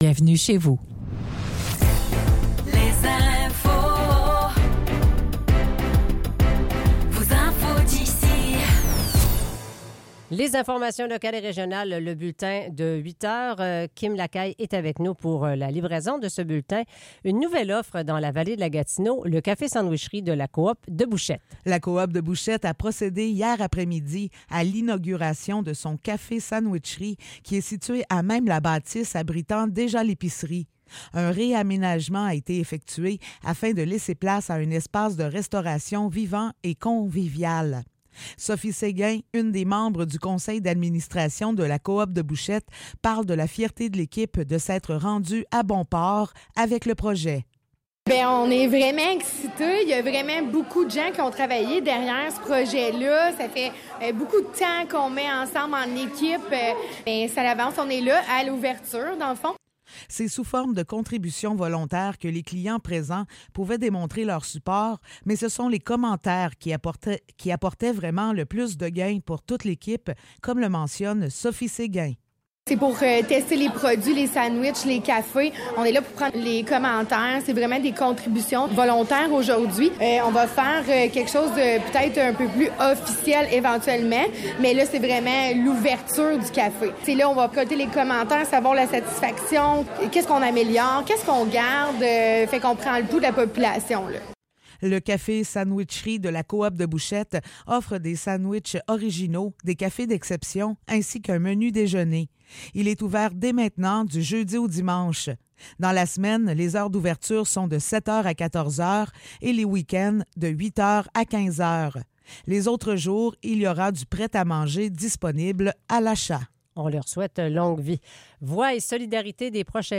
Nouvelles locales - 21 juin 2024 - 8 h